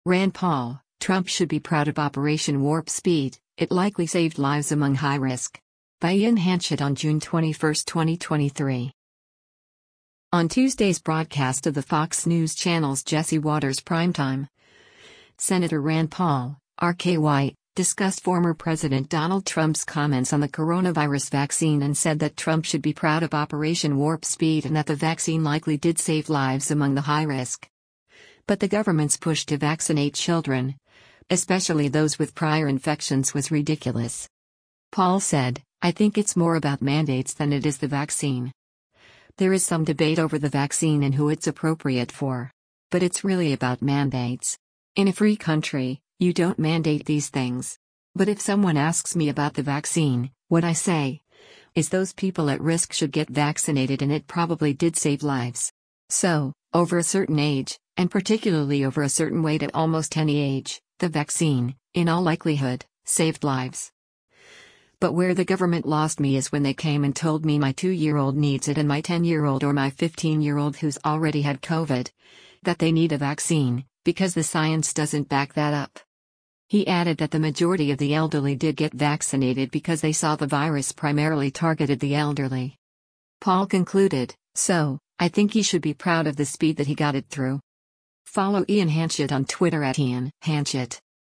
On Tuesday’s broadcast of the Fox News Channel’s “Jesse Watters Primetime,” Sen. Rand Paul (R-KY) discussed former President Donald Trump’s comments on the coronavirus vaccine and said that Trump should be “proud of” Operation Warp Speed and that the vaccine likely did save lives among the high-risk.